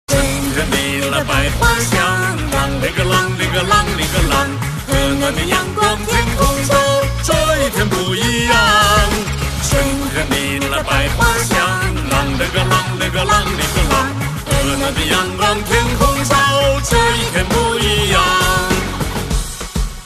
M4R铃声, MP3铃声, 华语歌曲 106 首发日期：2018-05-15 11:22 星期二